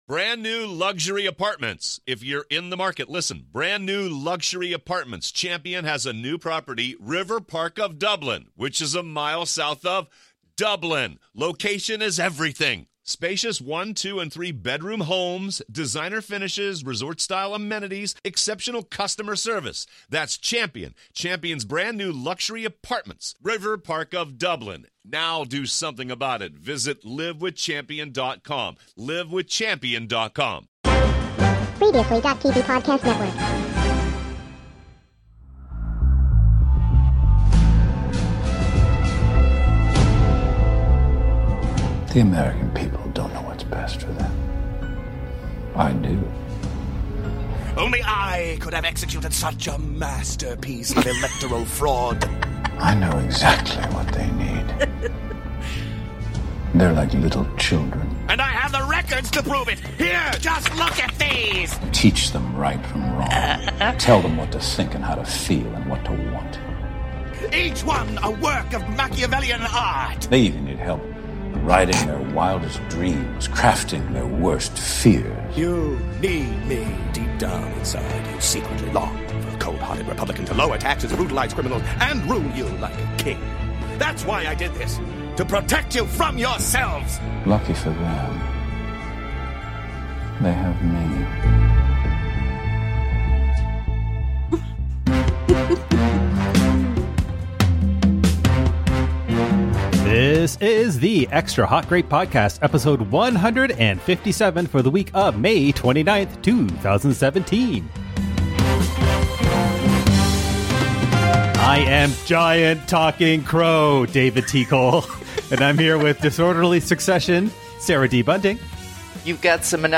The Big Bang Theory won, a very big human lost, and we put on our best announcer voices to pair off presenters in this week's Game Time.